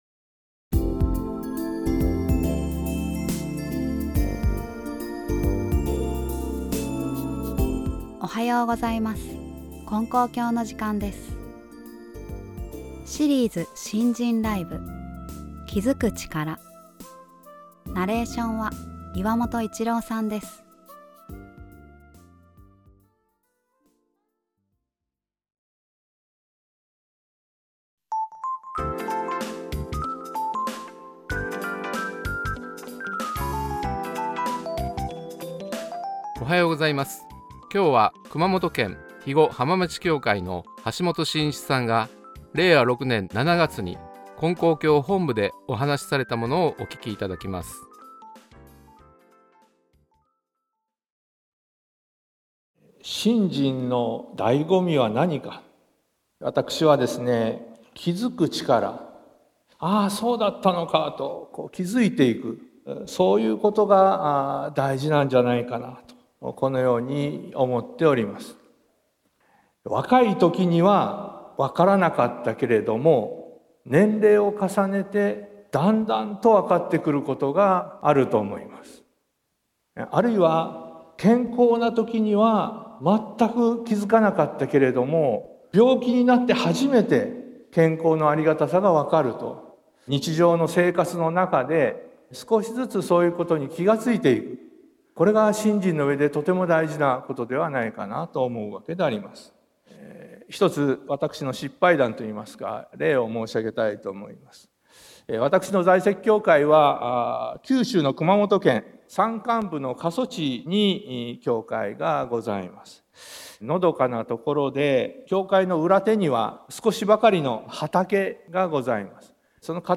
●信心ライブ